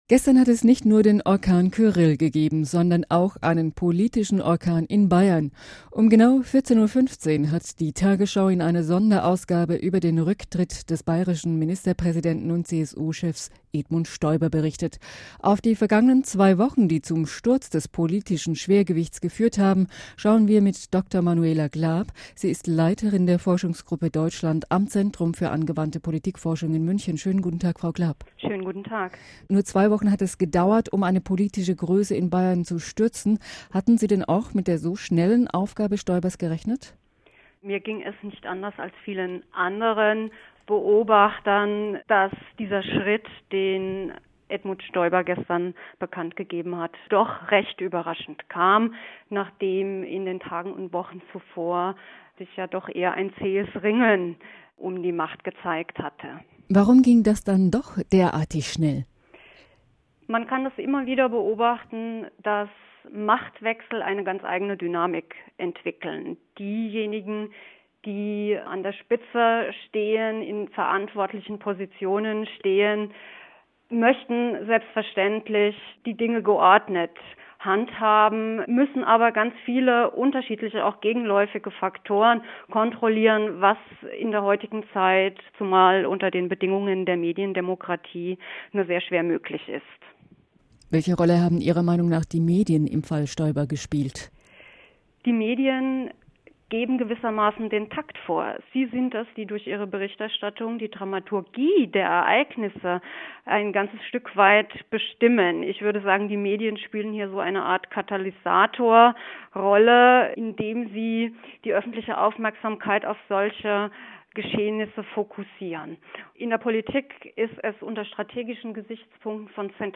Medien als Katalysator des Machtwechsels - Audio-Interview